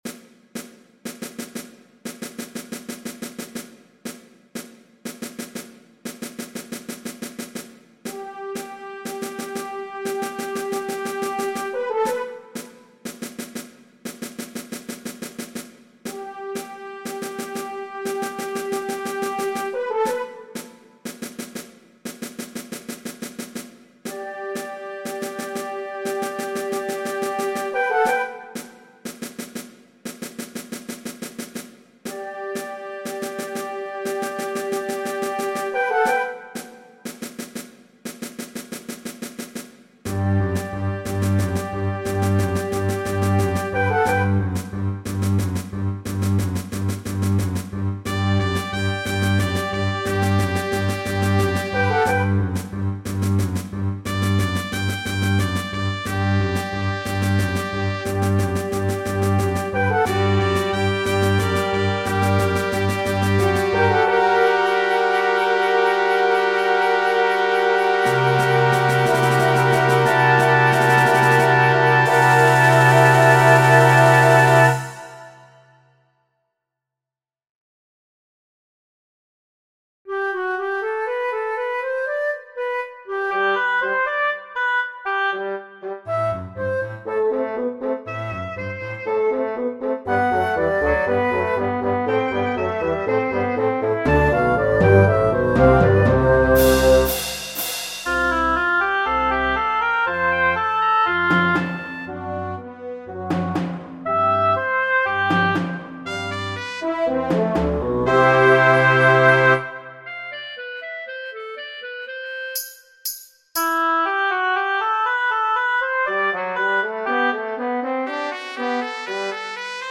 In het begin van mijn stuk hoor je soldaten.